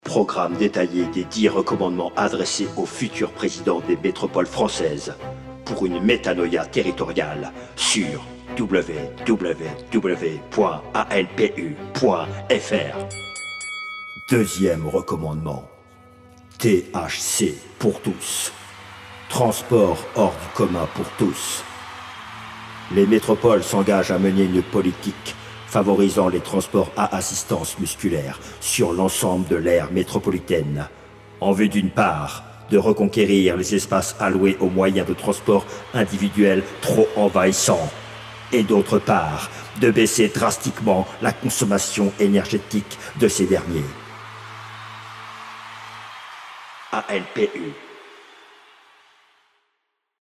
Jingle 1er recommandement